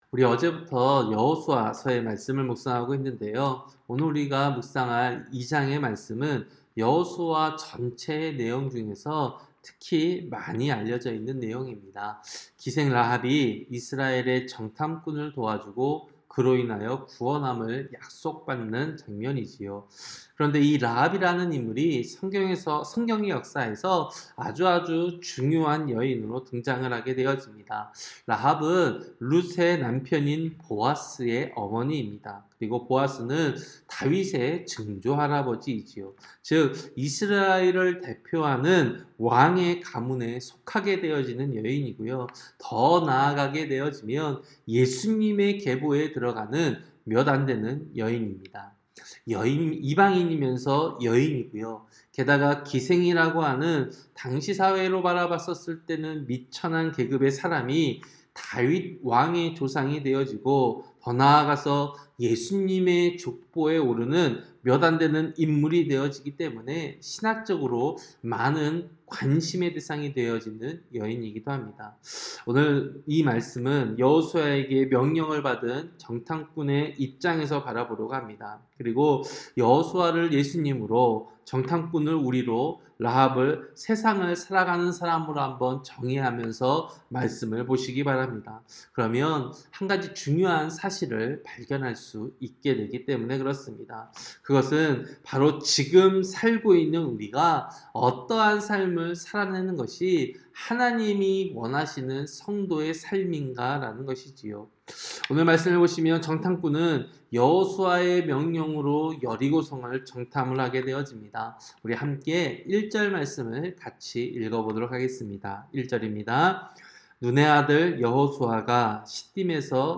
새벽설교-여호수아 2장